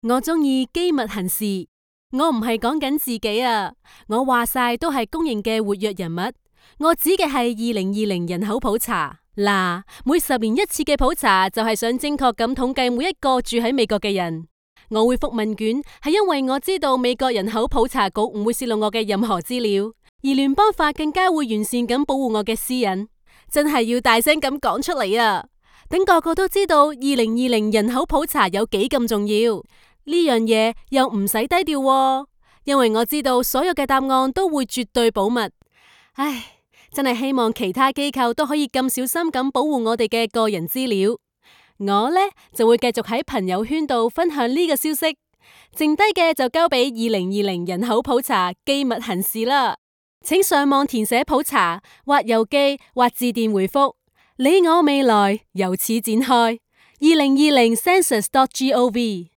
All our voice actors are premium seasoned professionals.
Explainer & Whiteboard Video Voice Overs
Adult (30-50) | Yng Adult (18-29)